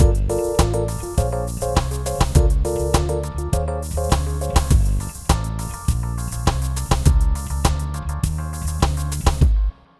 Ala Brzl 1 Fnky Full-G.wav